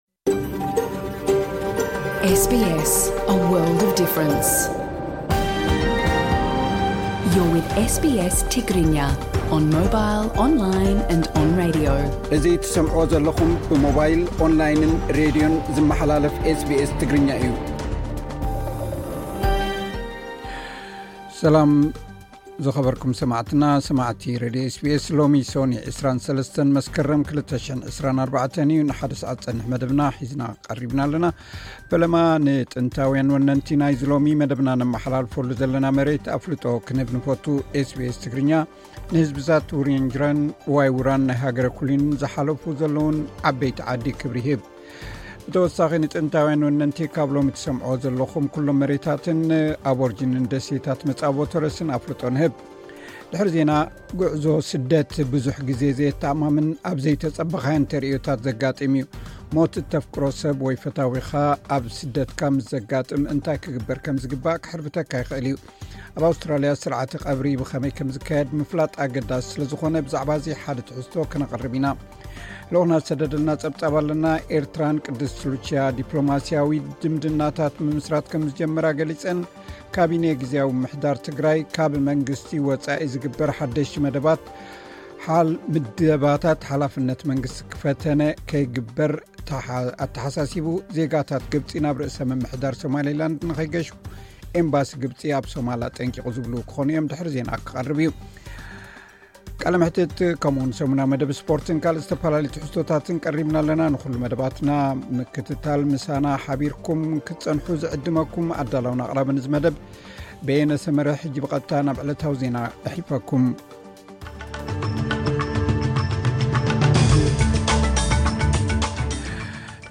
ዕለታዊ ዜና ኤስ ቢ ኤስ ትግርኛ (23 መስከረም 2024)